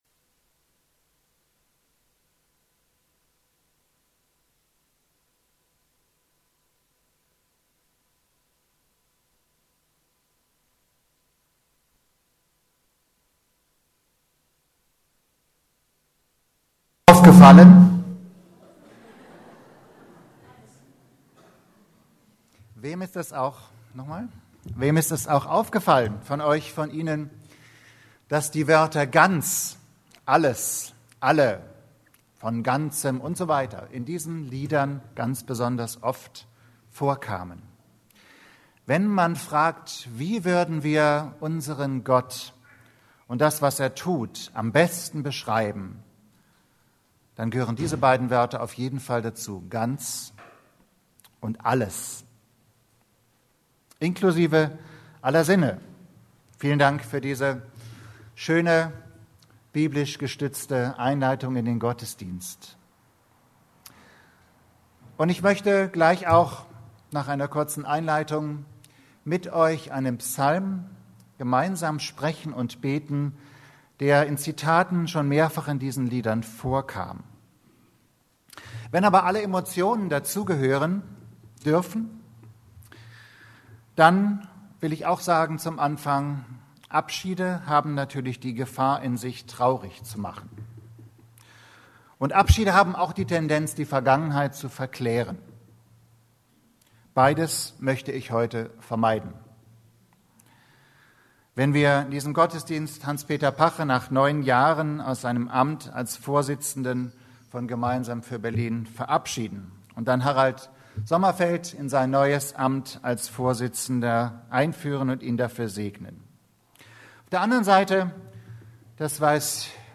Kommt und seht! ~ Predigten der LUKAS GEMEINDE Podcast